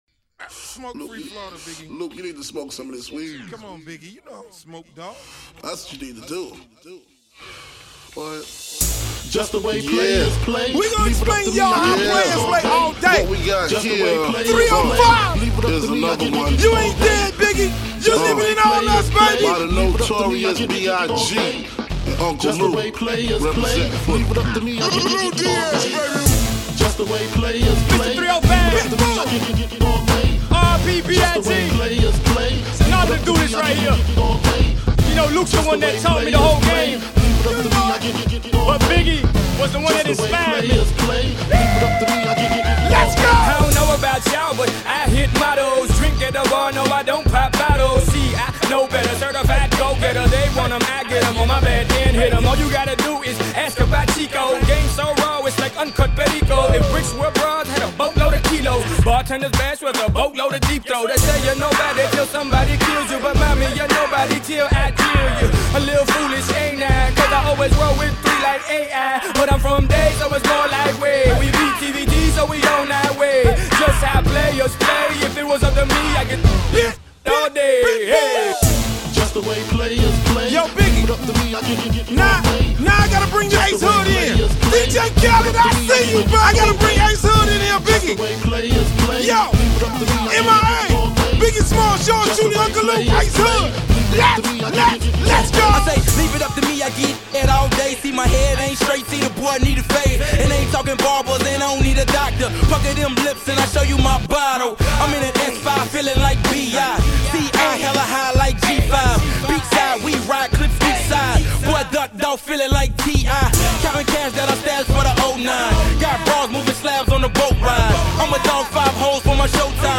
Genre: Reggaeton.